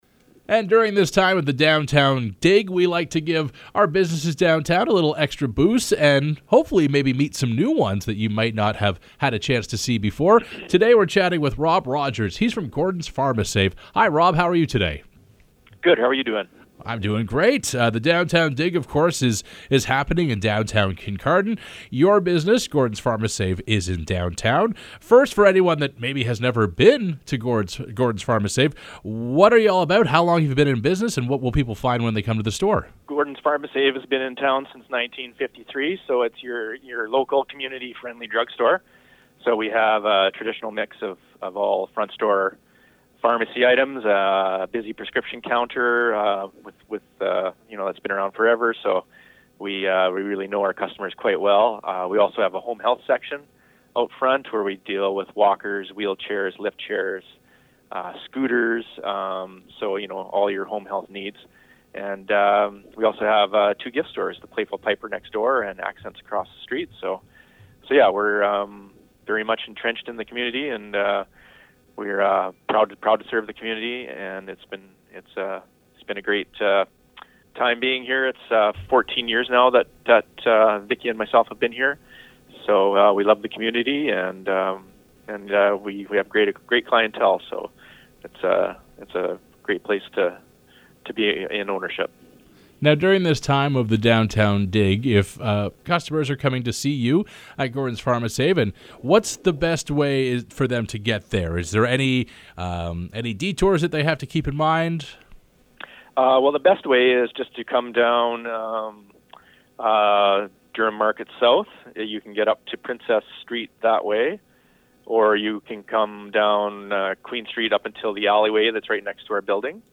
on the Shoreline Classics FM Morning Show